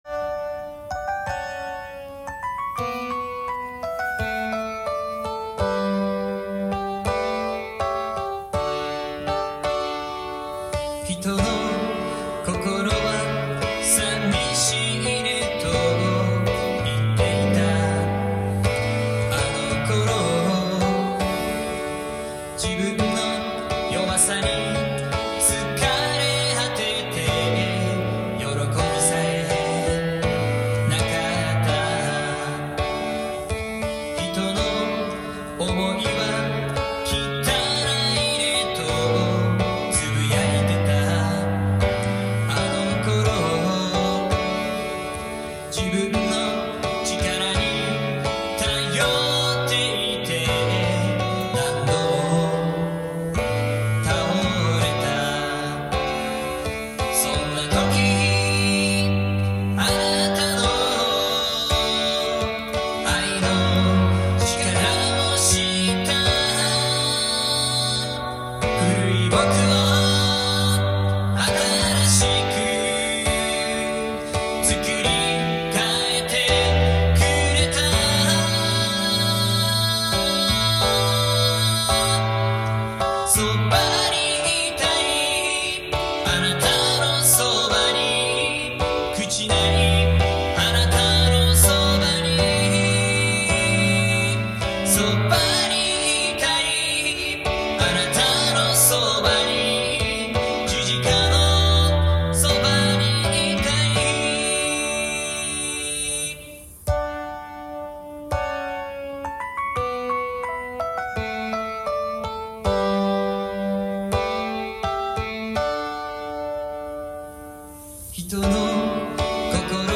その後、聖書学校時代に録音した賛美です！